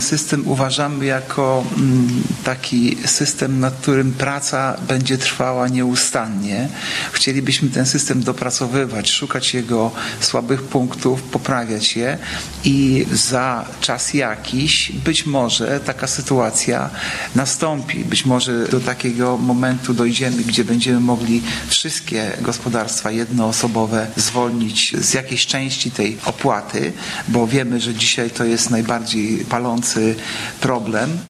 Pytaliśmy o to na antenie Radia 5 wiceprezydenta Ełku Artura Urbańskiego.